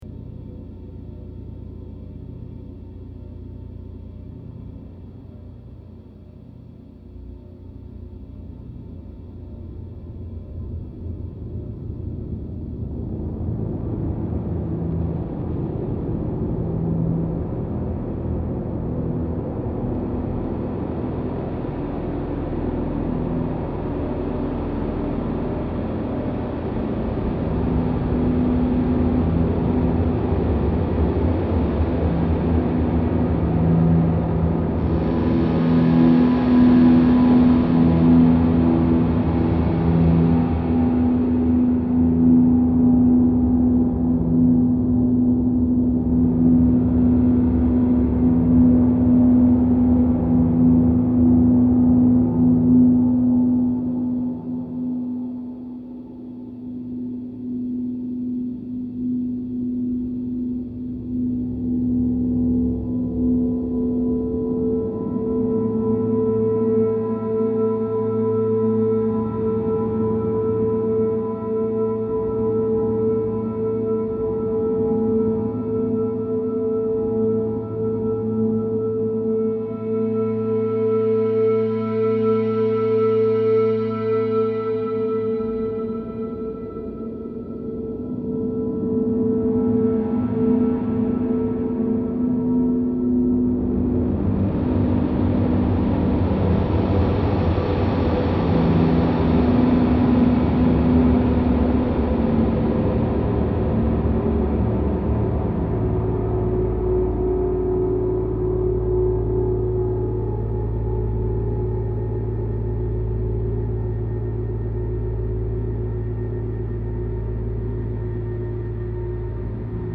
Abstract Ambient